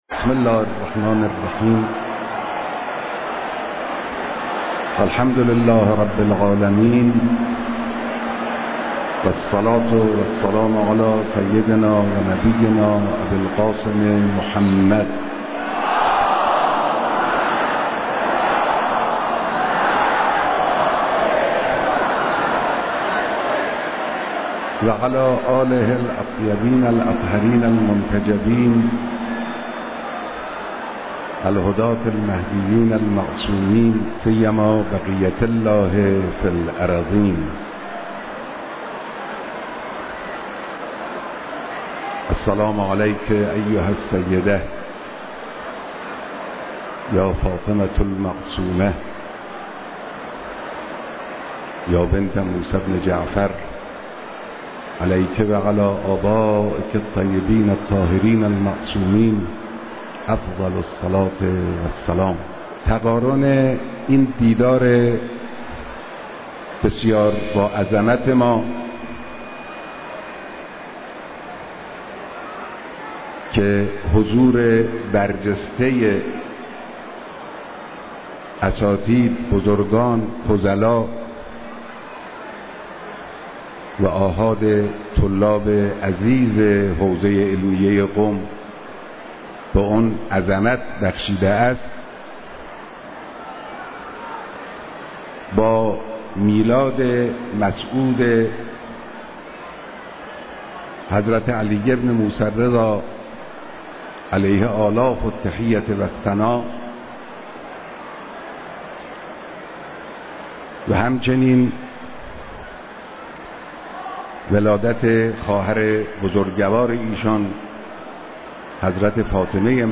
اجتماع بسیار عظیم و پرشور علما، فضلا، اساتید، مدیران مدارس و طلاب حوزه علمیه قم
بيانات در ديدار طلاب، فضلا و اساتيد حوزه علميه قم